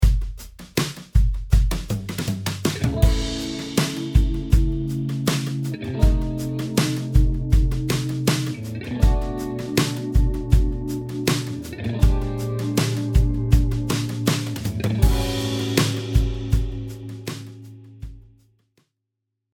Finally, here is a really nice blues intro that could also be used for a turnaround or ending. This approach creates a descending chromatic movement towards the I7 resolution chord (A13).
Tritone-Substitution-Blues-Turnaround.mp3